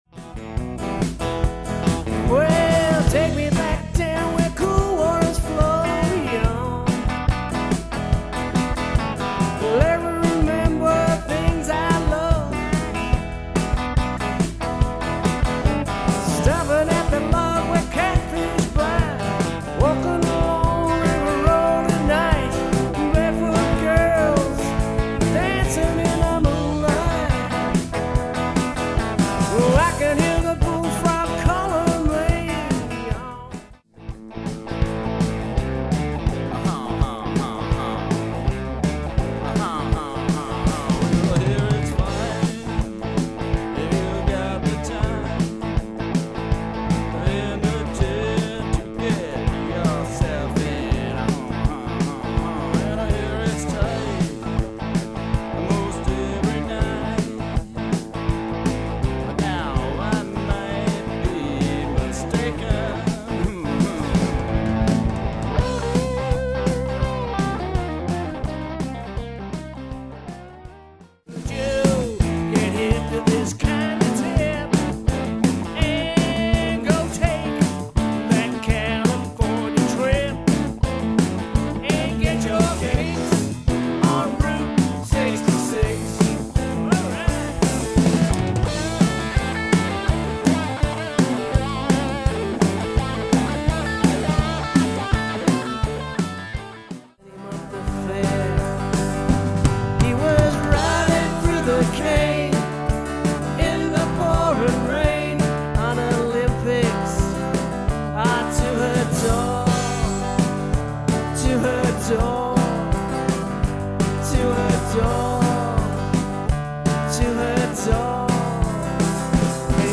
If you’re looking for a party band to raise the roof
guitar band
Then the rock’n’roll cranks up